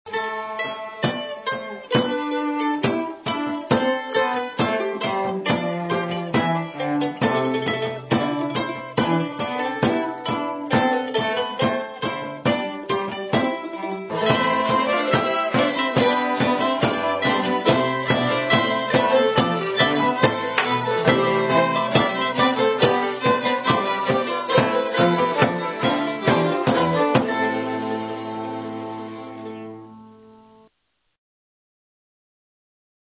Mandolins
Octave Mandolin
Violin
Viola
Violoncello
Bodhran